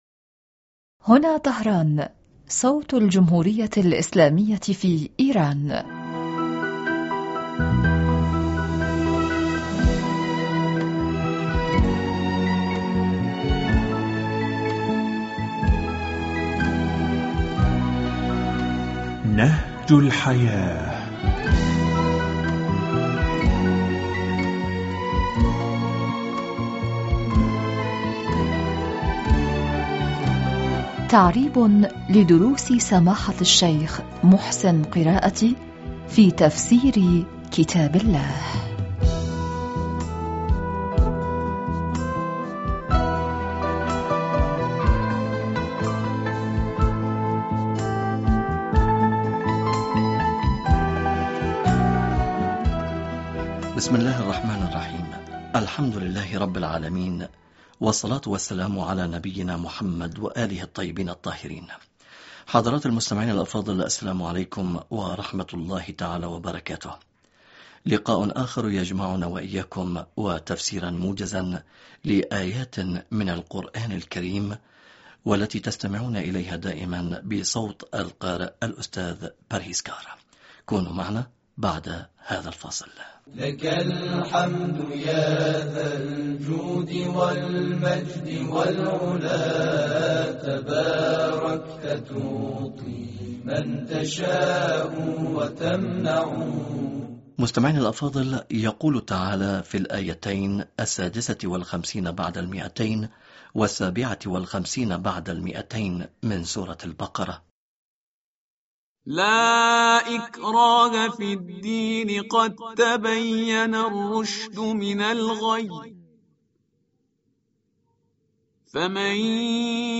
فــــــاصـــــل ويقول تعالى في الآية الثامنة والخمسين بعد المئتين من سورة البقرة: ألم تر ..................